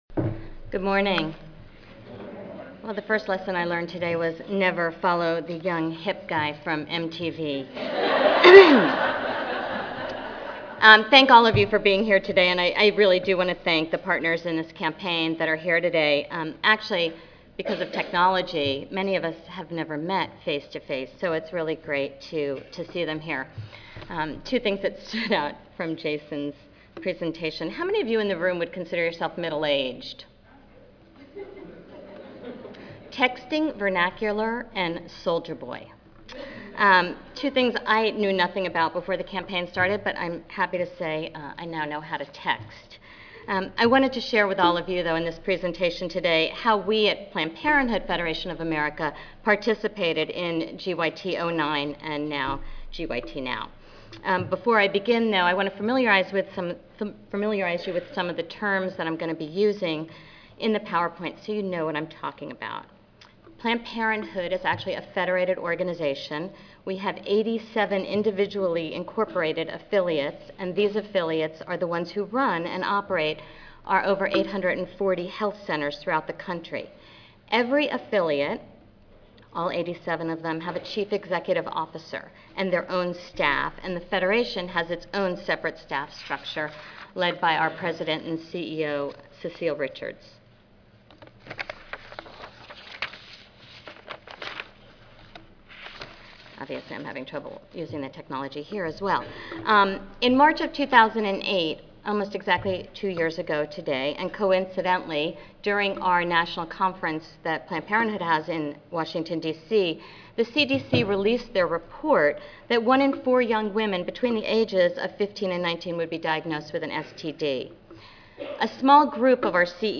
DC Audio File Recorded presentation